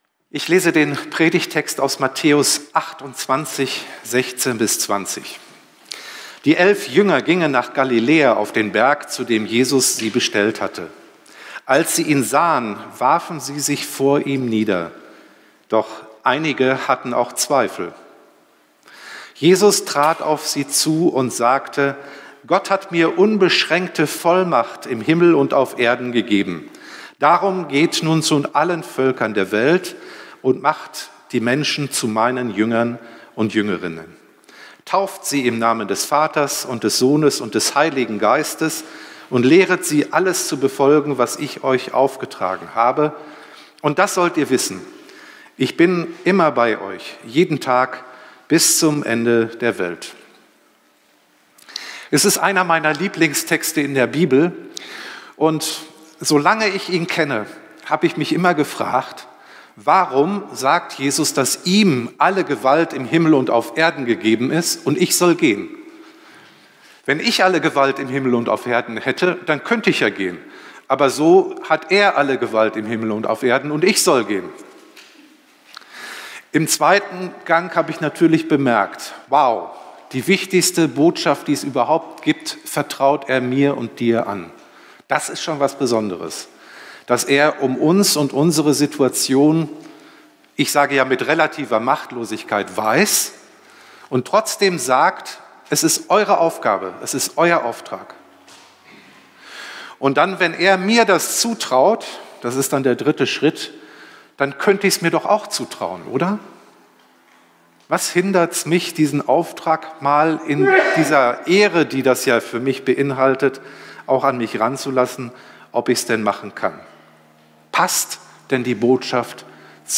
04-Predigt-8.mp3